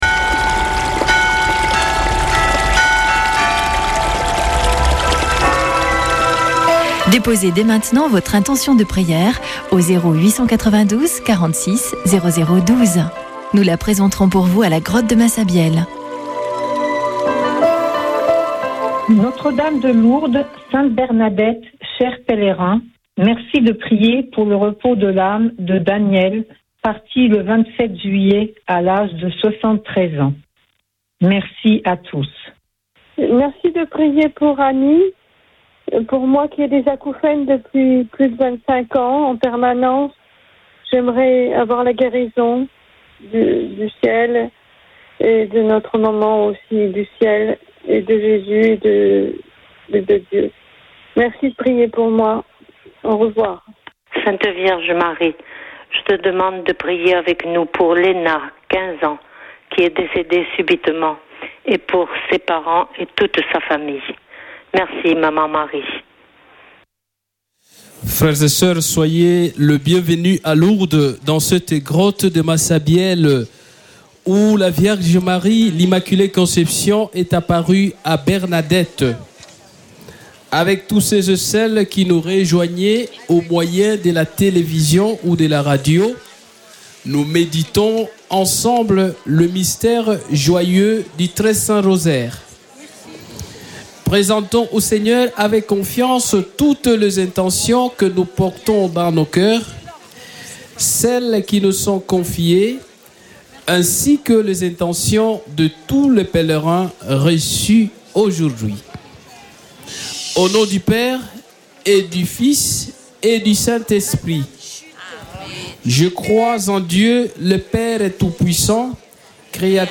Chapelet de Lourdes du 28 juil.